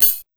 FX140CYMB1-R.wav